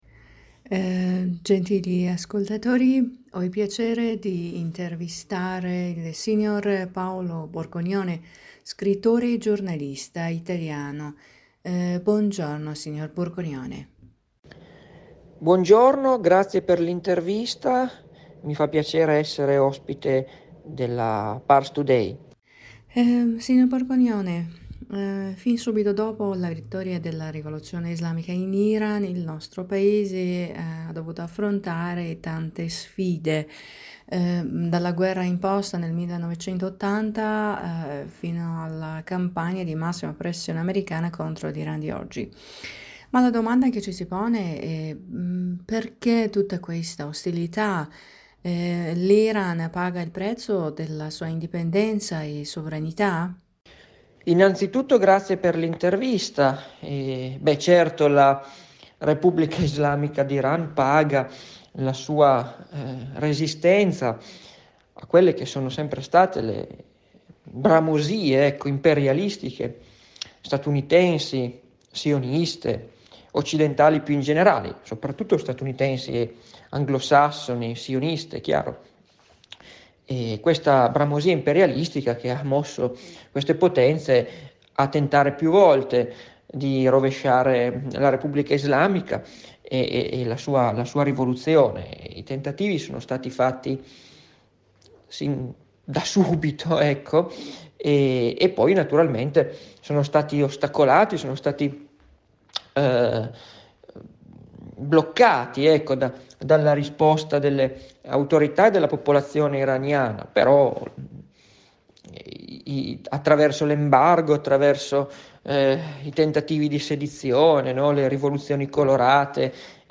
in un collegamento telefonico con la Radio Italia della Voce della Repubblica islamica dell'Iran (IRIB)
Per ascoltare la versione integrale dell’intervista cliccare qui sopra